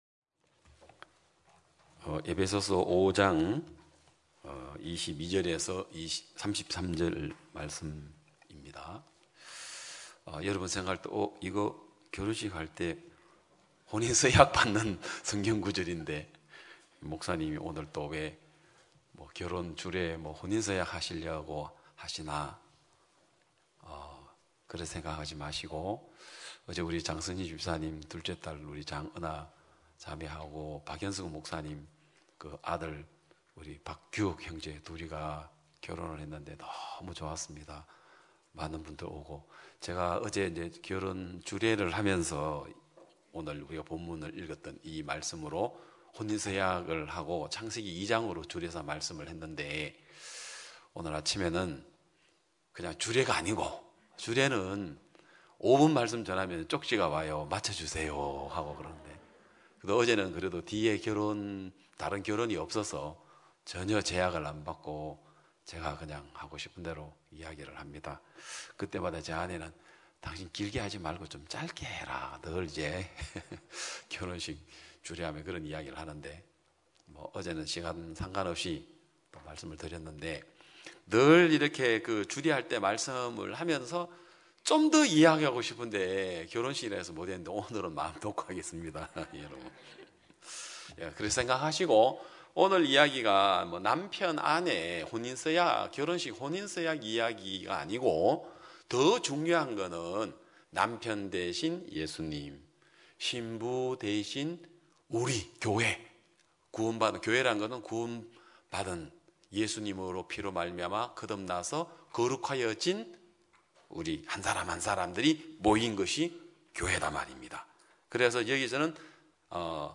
2022년 4월 24일 기쁜소식양천교회 주일오전예배
성도들이 모두 교회에 모여 말씀을 듣는 주일 예배의 설교는, 한 주간 우리 마음을 채웠던 생각을 내려두고 하나님의 말씀으로 가득 채우는 시간입니다.